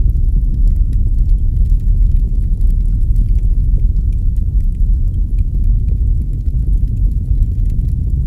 fire_idle.ogg